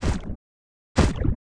Index of /App/sound/monster/misterious_diseased_spear
drop_2.wav